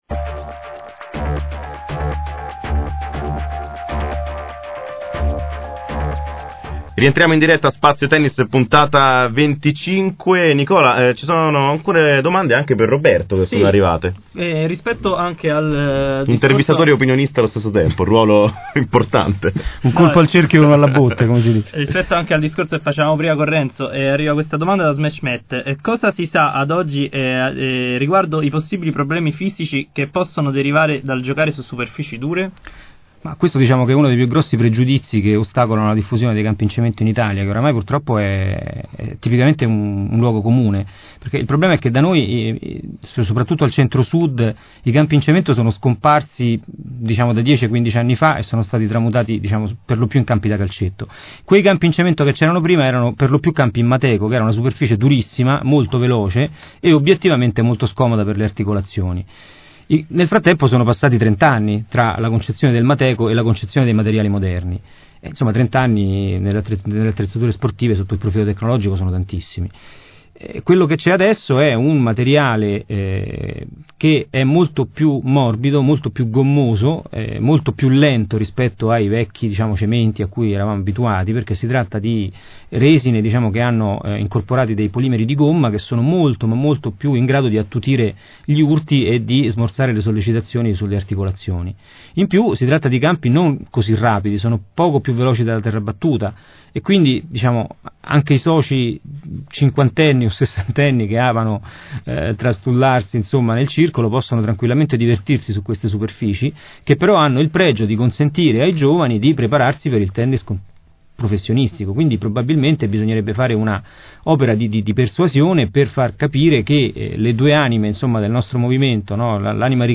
Ecco a voi la replica della 25esima puntata di Spazio Tennis, andata in onda ieri sera, dalle 22 alle 23, su Nuova Spazio Radio.